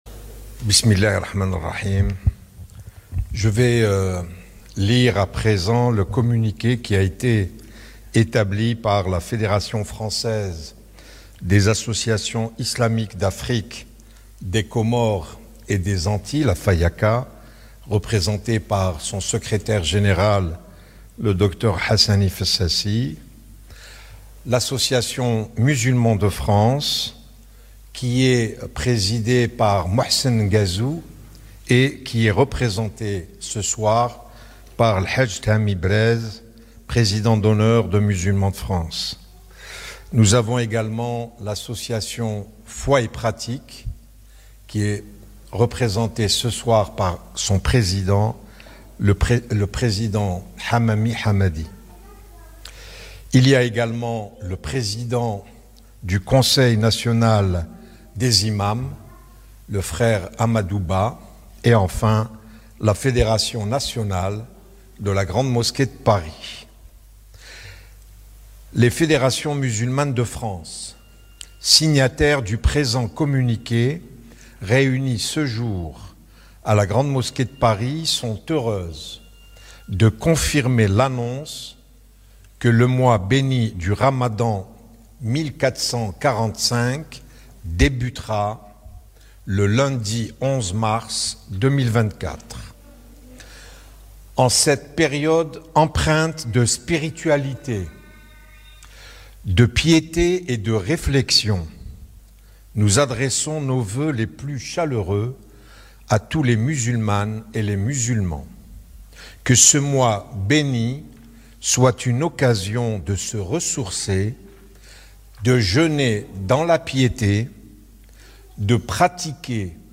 Communiqué lu par Chems Eddine Hafiz, recteur de la Grande Mosquée de Paris.
Le mois de ramadan commence en France ce lundi 11 mars 2024 . L’annonce a été faite lors de la nuit du doute, depuis la Grande Mosquée de Paris, une annonce retransmise en direct sur Radio Orient.